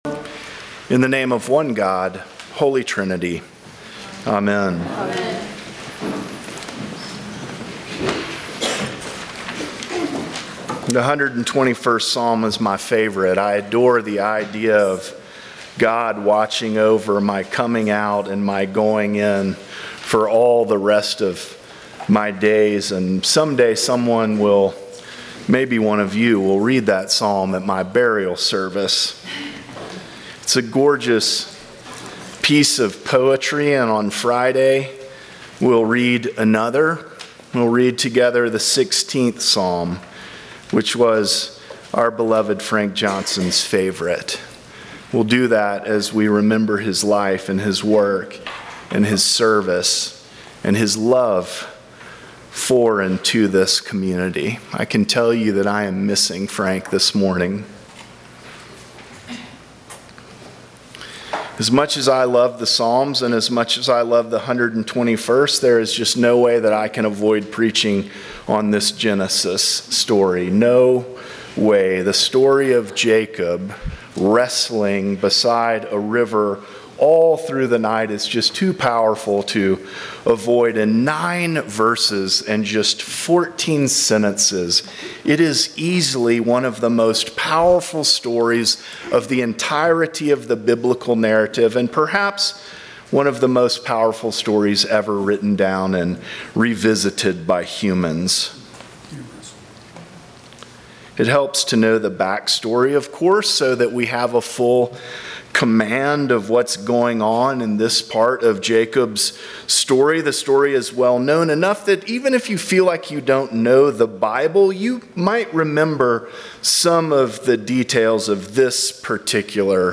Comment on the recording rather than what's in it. St. John's Episcopal Church